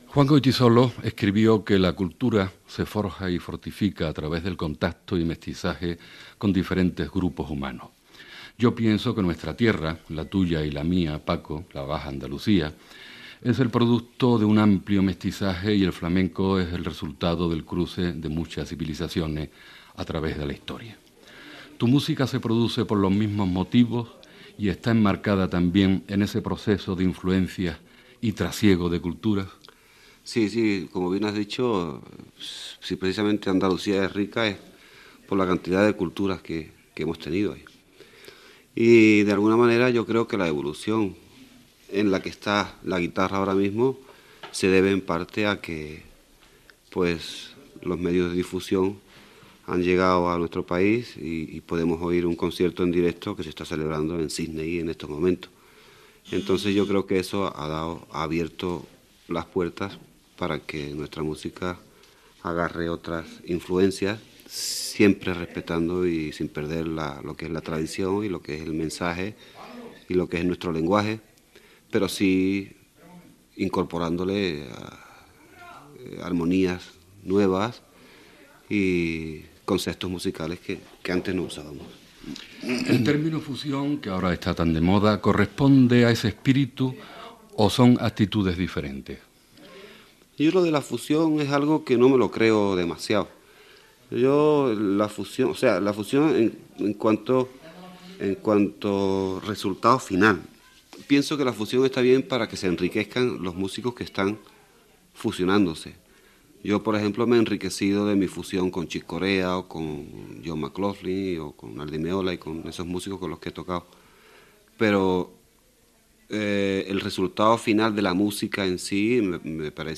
Entrevista al guitarrista Paco de Lucía sobre la seva trajectòria i la música flamenca, feta a la seva casa de Mirasierra de Madrid